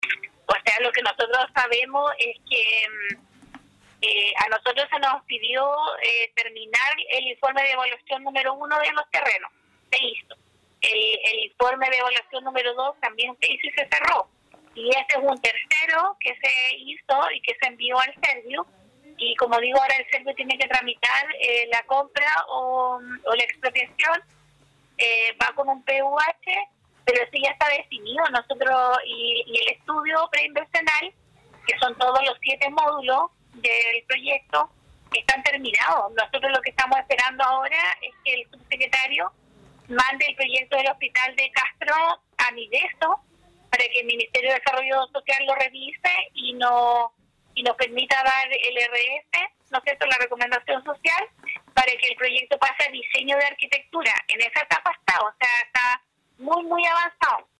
Así lo expresó la directora del Servicio de Salud Chiloé, Marcela Cárcamo.